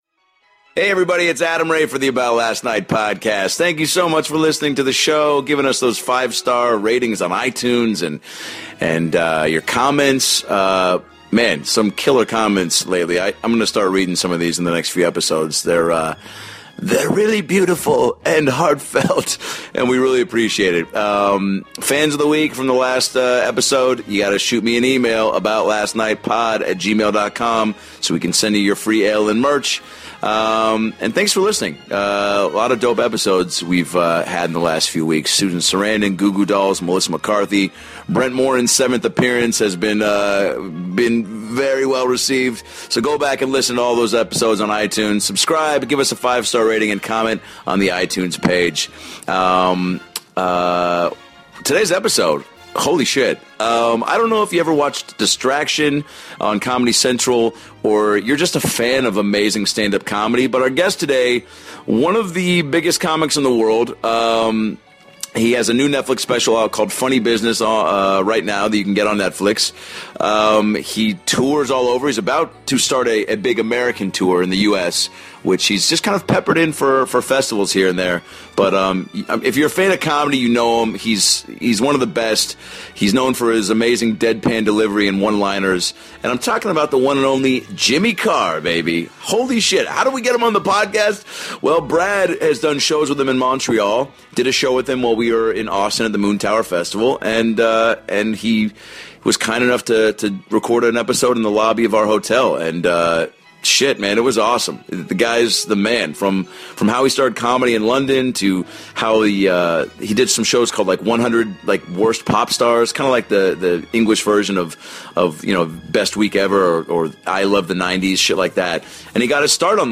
Facebook Twitter Headliner Embed Embed Code See more options The great Jimmy Carr makes his ALN debut, and gives us all a reminder why he's still one of the best in the game! From small shows in the UK, to theaters across the globe, his deadpan delivery and quick one liners have made him not only a favorite among comics, but audiences everywhere.